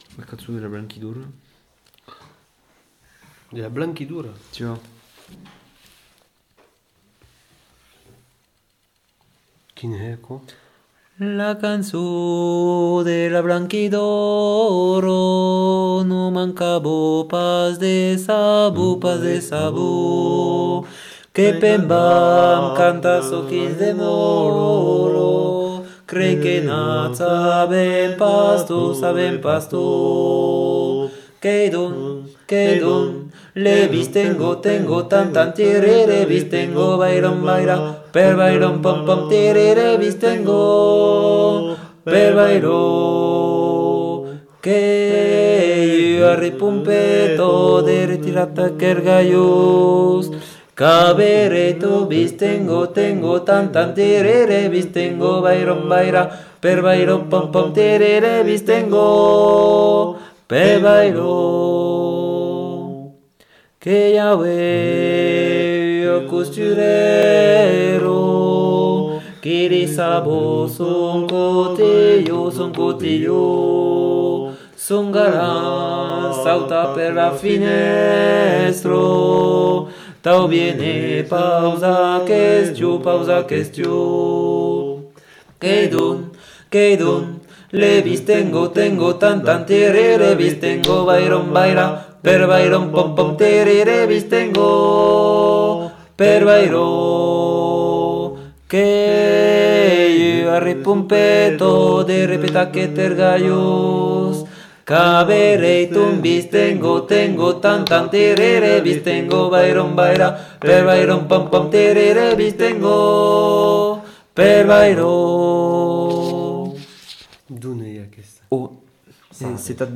Aire culturelle : Bigorre
Lieu : Bénac
Genre : chant
Effectif : 2
Type de voix : voix d'homme
Production du son : chanté
Descripteurs : polyphonie